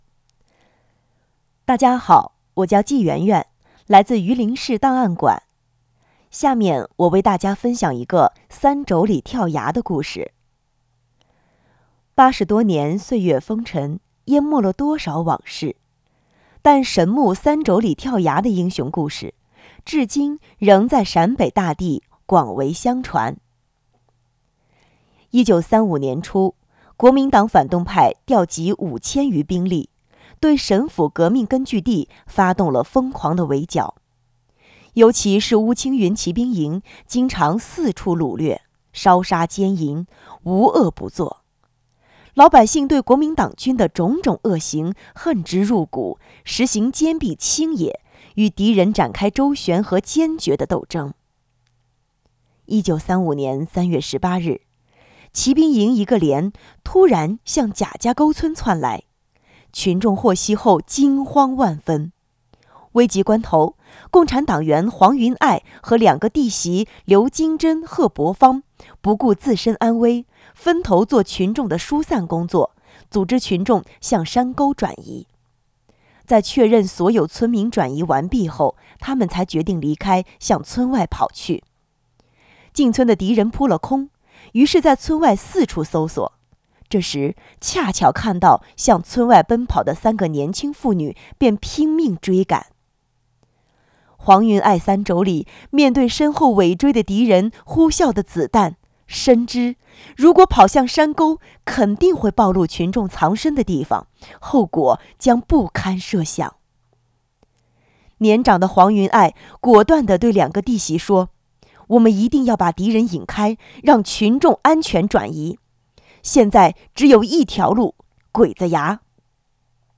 【红色档案诵读展播】神木三妯娌跳崖